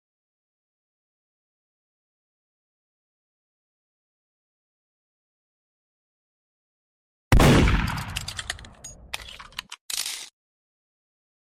Mm2 knife throwing and gun shooting green screened